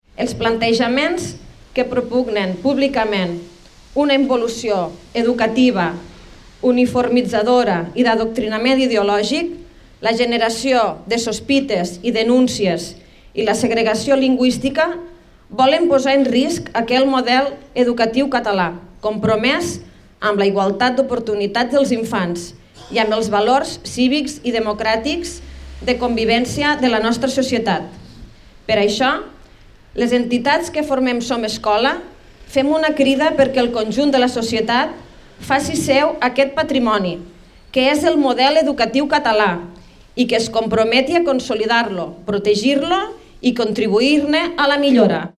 En el manifest llegit durant la concentració, es rebutjava la voluntat del govern espanyol de voler «trencar» la cohesió social i «dividir» la societat.